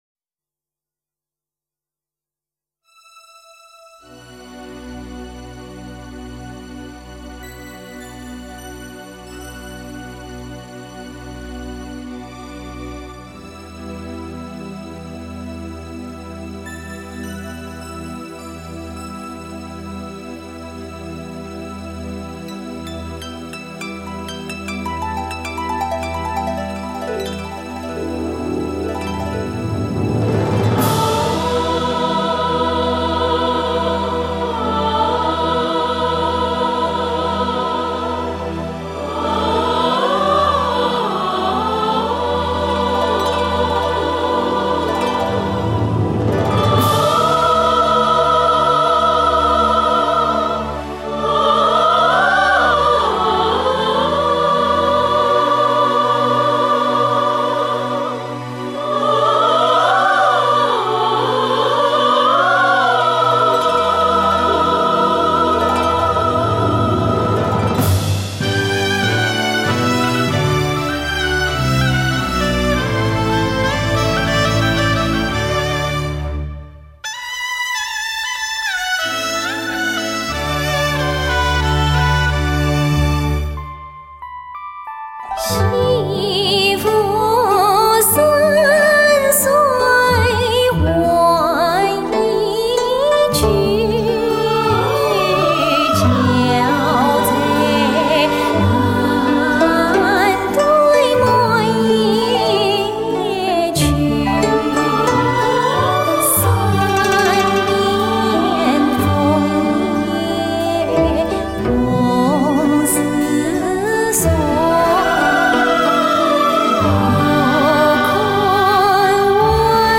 白素贞唱段